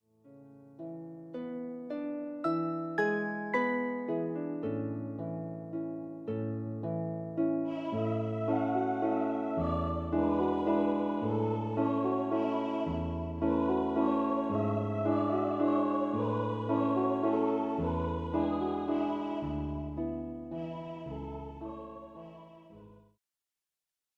for voice and pedal harp
Mezzo Soprano